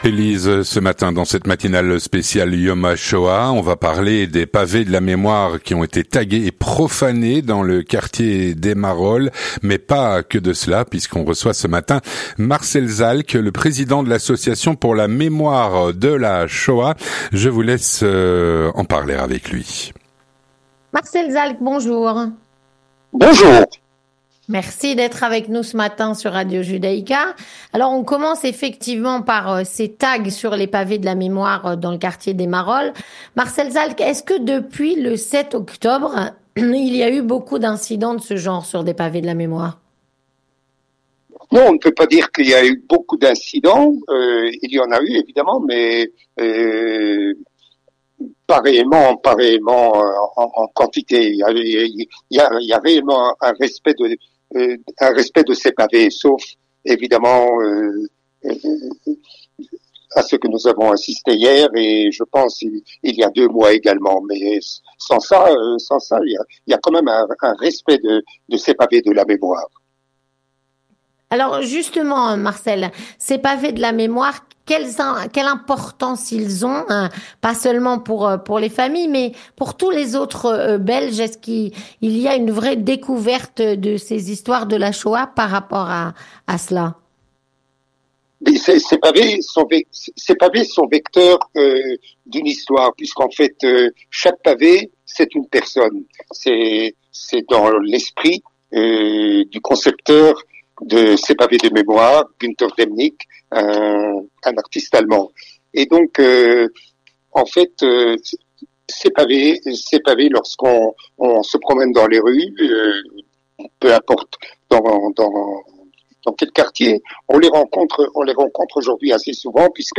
L'interview Communautaire - Des Pavés de la mémoire tagués et profanés dans le quartier des Marolles.